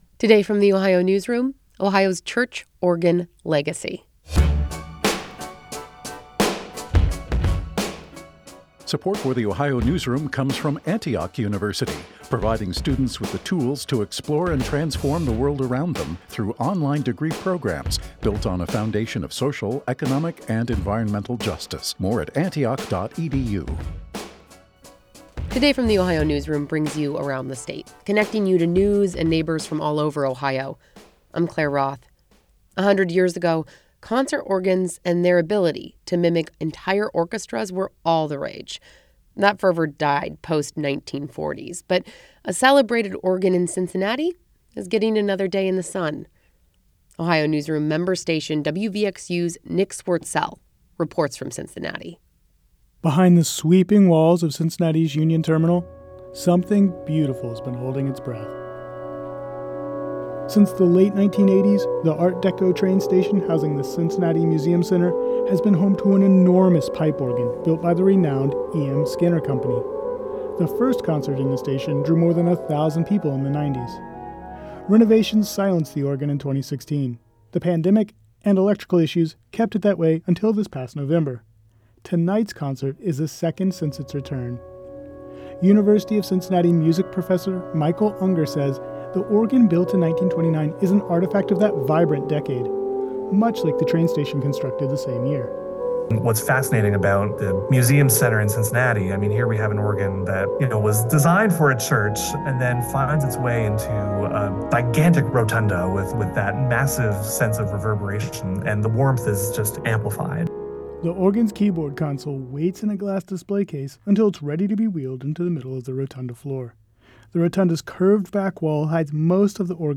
The hum of air compressors grows louder as he enters the room.
Combined, the setup gives listeners an immersive, surround sound experience.